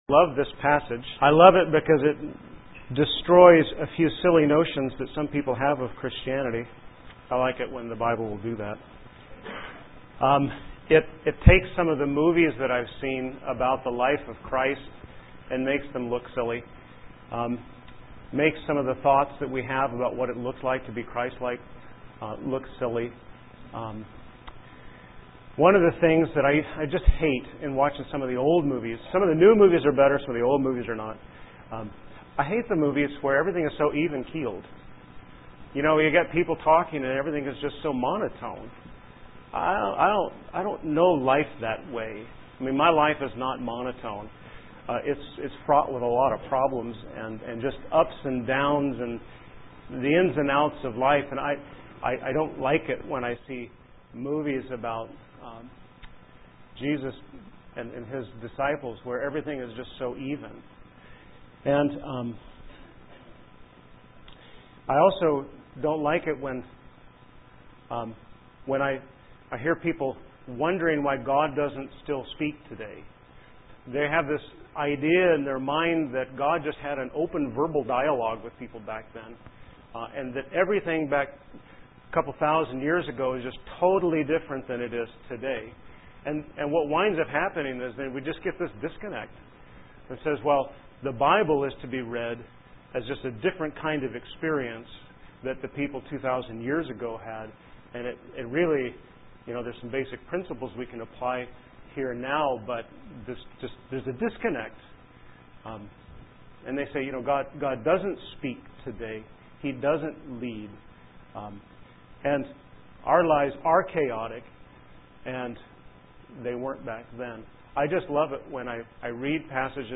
Evangelical Free Church in Washington State